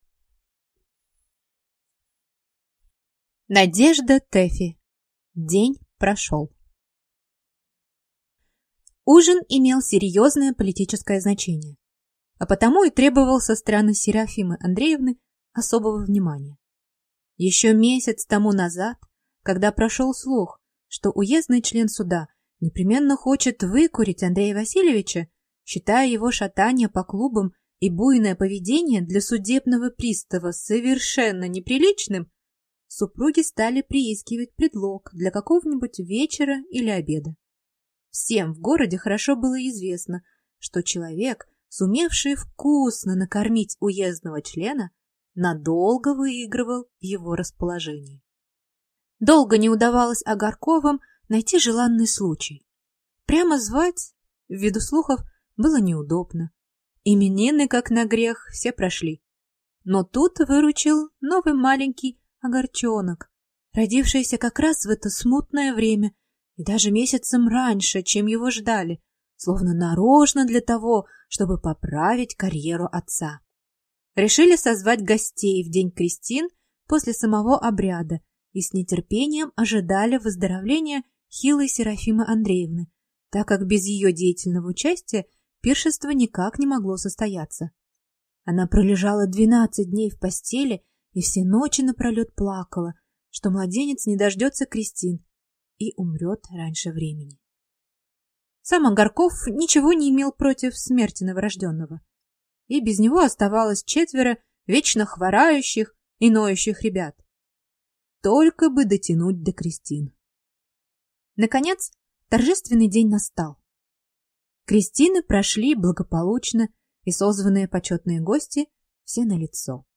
Аудиокнига День прошел | Библиотека аудиокниг